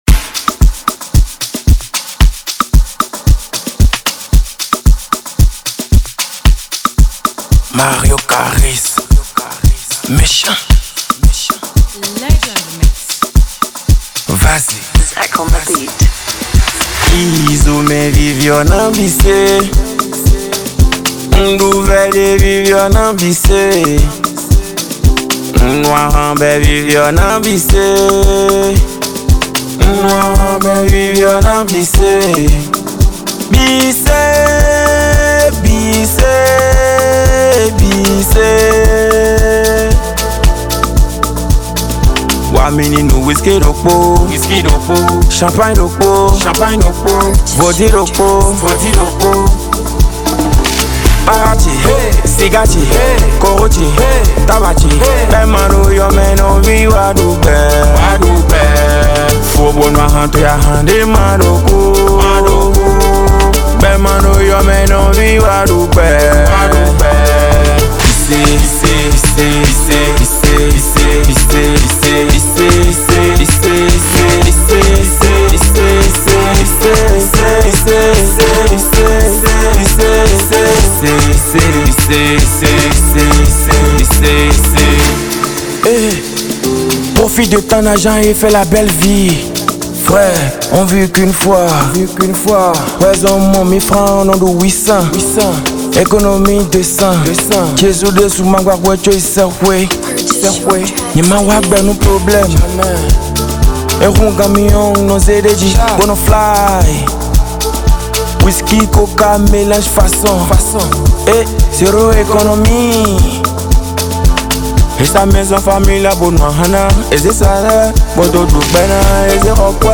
Urban Mp3